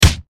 punch1.ogg